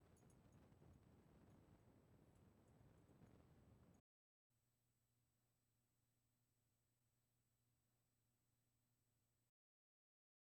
at first there was so much noise, I thought "this doesnt work for me". then I thought, its Tube , let it warm up...so left and came back and still it was so noisy just idle.
But no worrys...the BlueCat showed what my ears heard , a huge noise floor drop.
mp3 is 10second, 0-3 JJ5751, 4sec-9 other tube
WA Tube noise gone.mp3